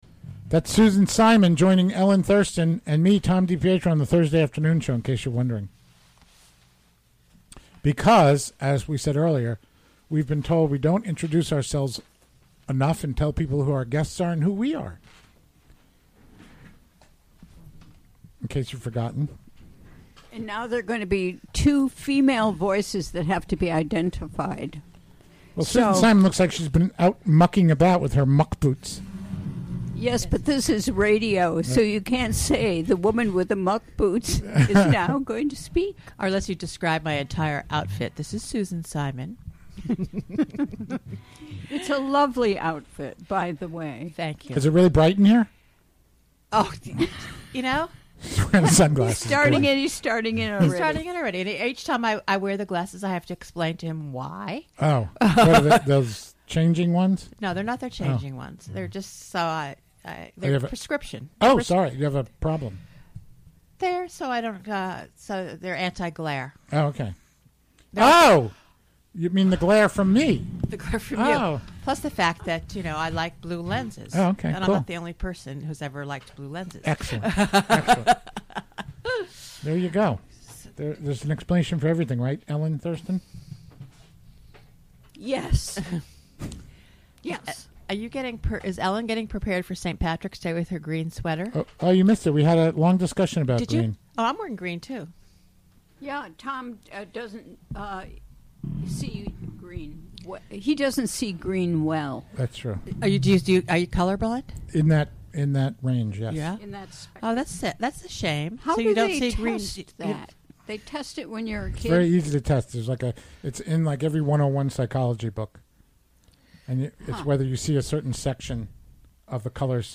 Recorded live on the WGXC Afternoon Show, Thurs., March 8, 2018.